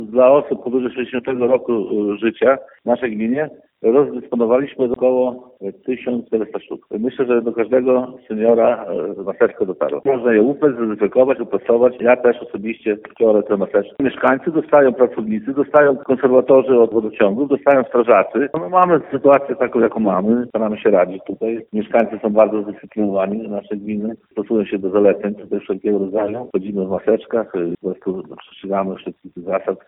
Jak mówi wójt Stanisław Pietrukiewicz, mieszkańcy gminy są bardzo zdyscyplinowani i stosują się do zaleceń.